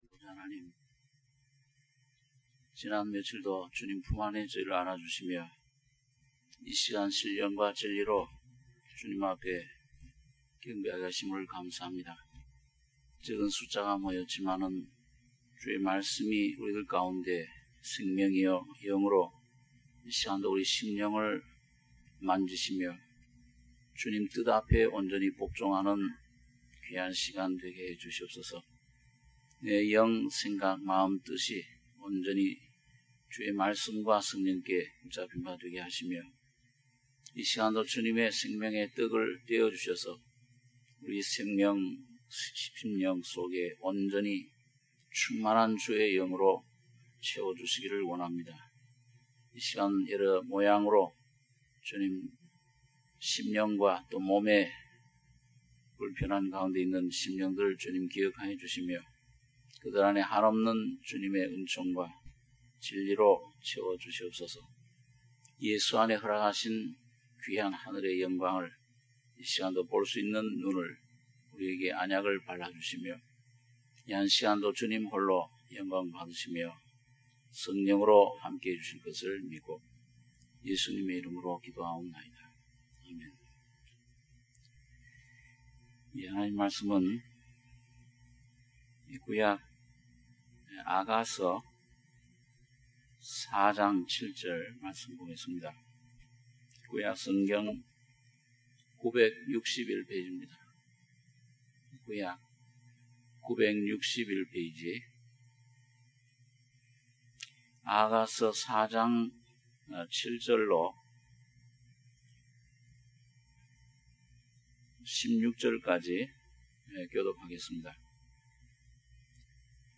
수요예배 - 아가 4장 7절 - 16절